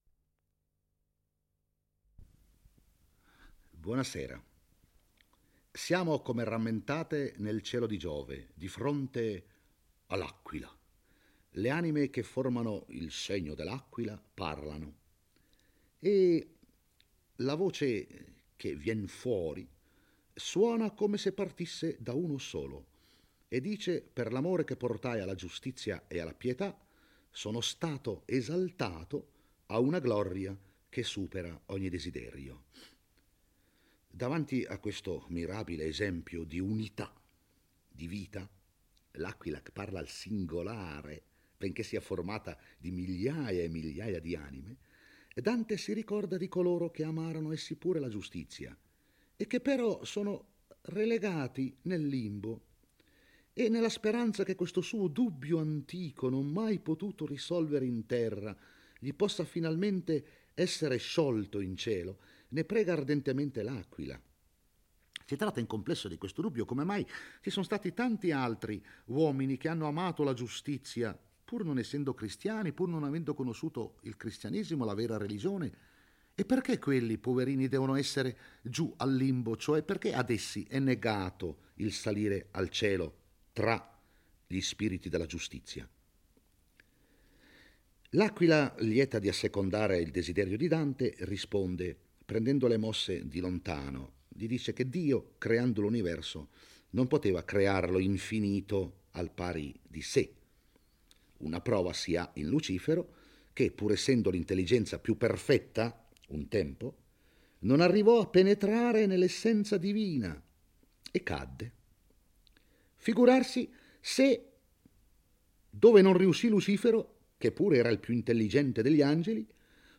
legge e commenta il XIX canto del Paradiso.